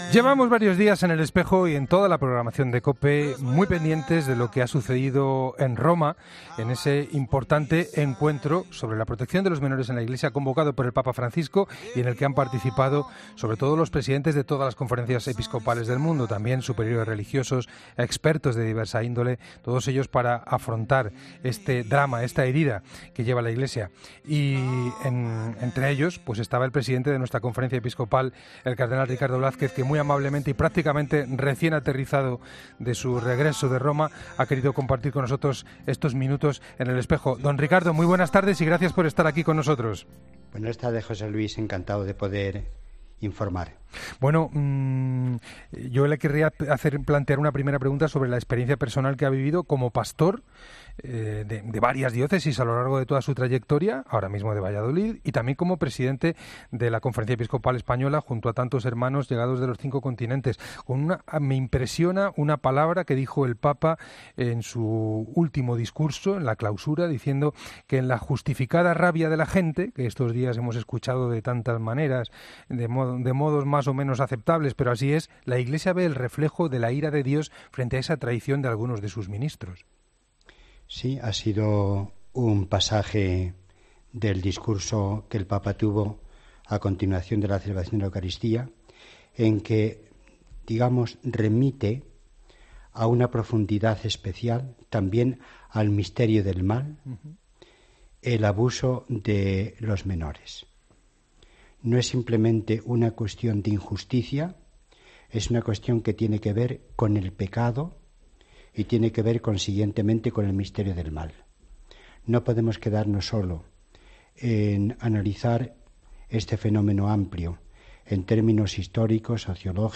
El presidente de la Conferencia Episcopal Española, el cardenal Ricardo Blázquez ha hecho balance sobre la cumbre para la protección de menores en El Espejo de COPE.